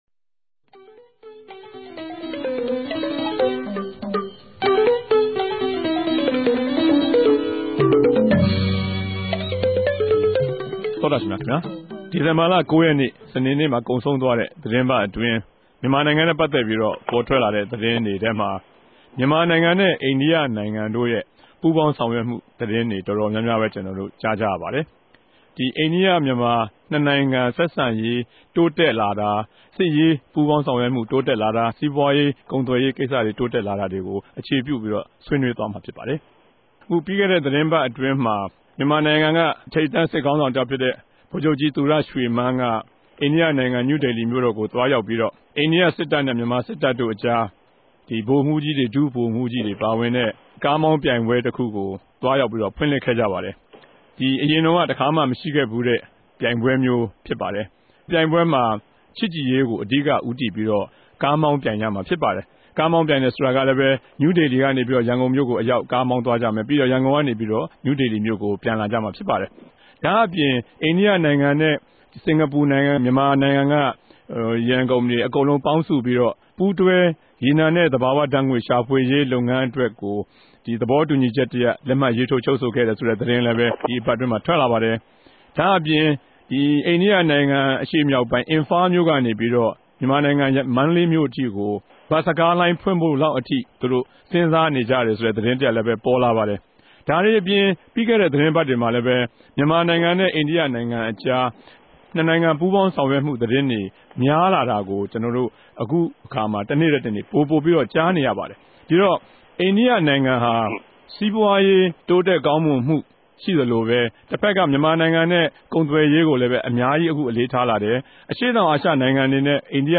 တပတ်အတြင်း သတင်းသုံးသပ်ခဵက် စကားဝိုင်း (၂၀၀၆ ဒီဇင်ဘာလ ၉ရက်)